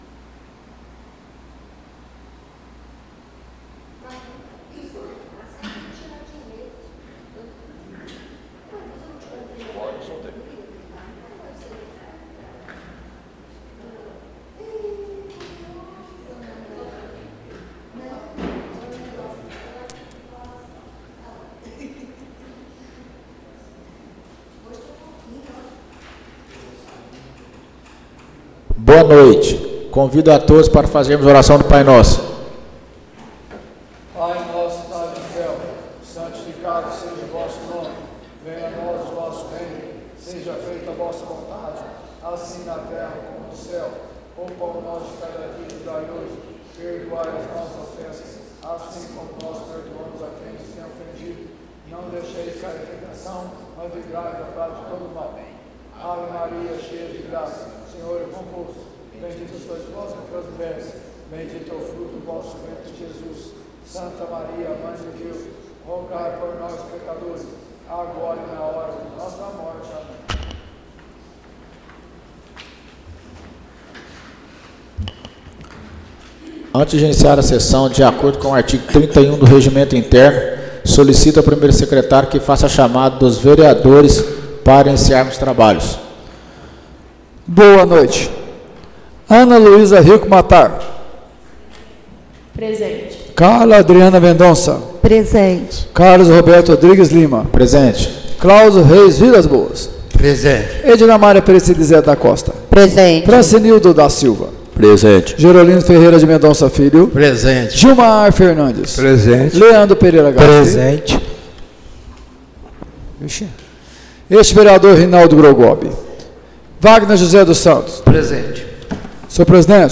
Áudio da Sessão Ordinária de 23/09/2024